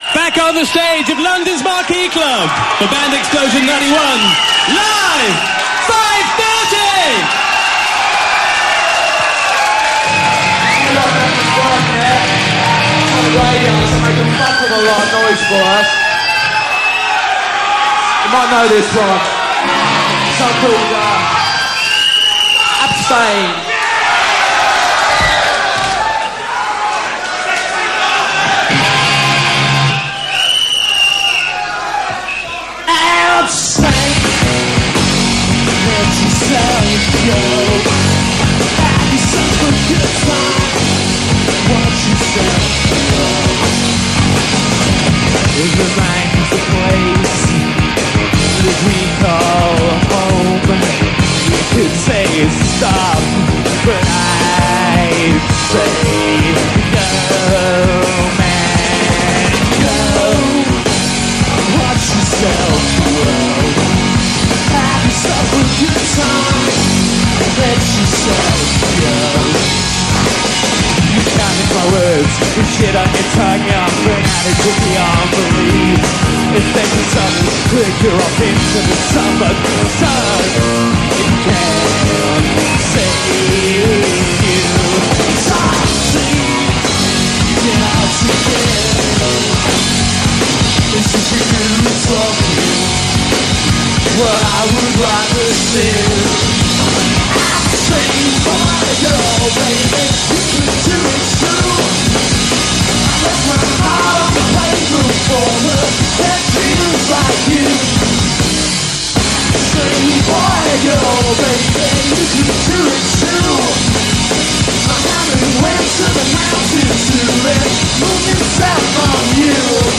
vocals, bass
vocals, guitar
drums
Armed with jagged guitars and pounding drums